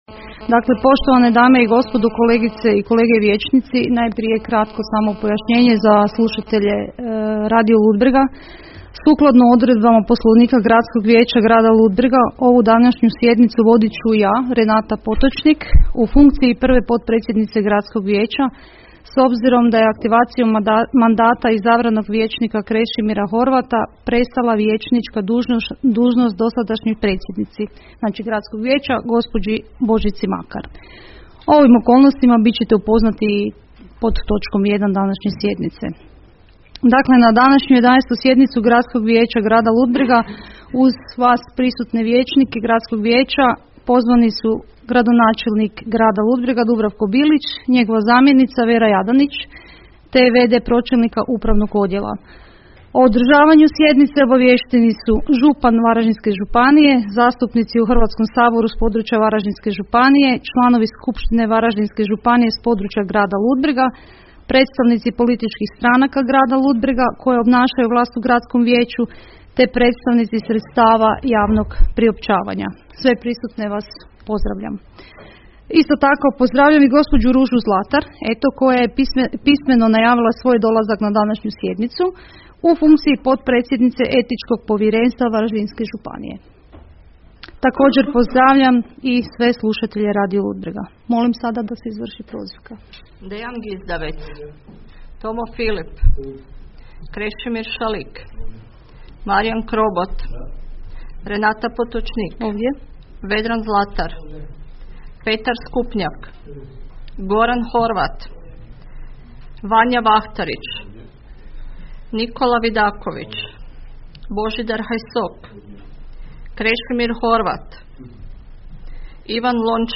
17. SJEDNICA GRADSKOG VIJEĆA